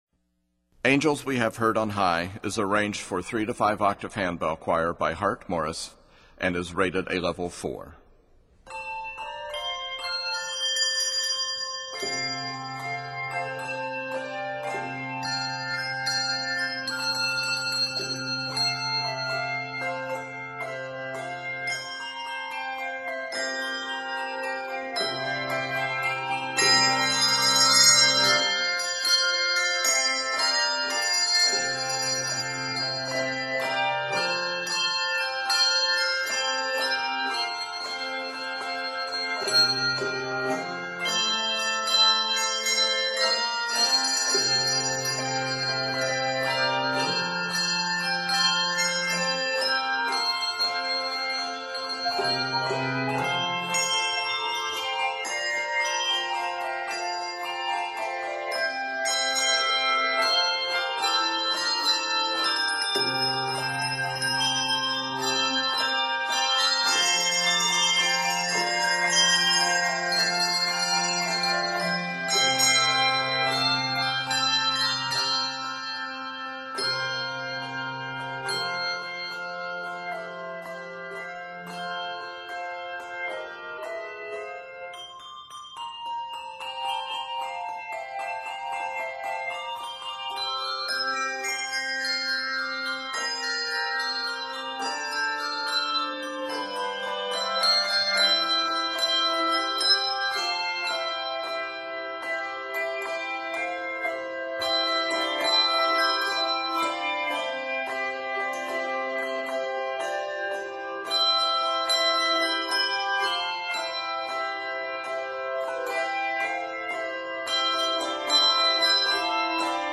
It is scored in F Major and C Major.
Traditional French Carol Arranger